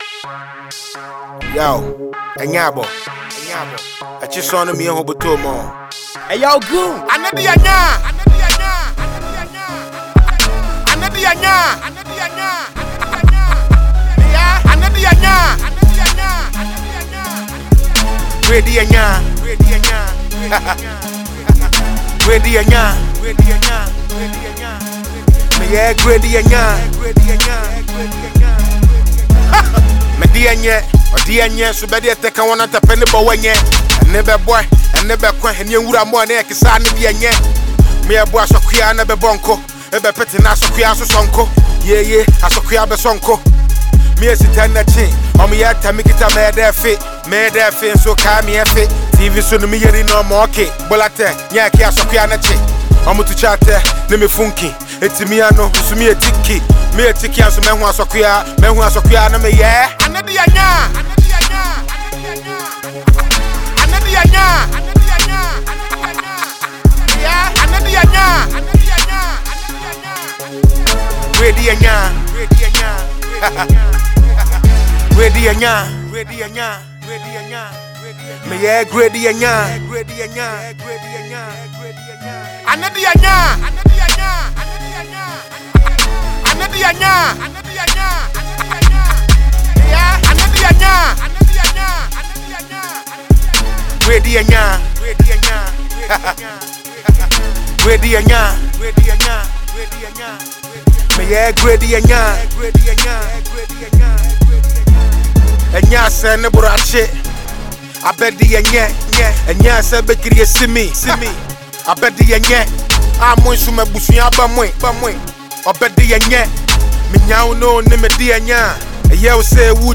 Hip-Hop …
Ghanaian MC
Hip-Life
Unadulterated Rap Music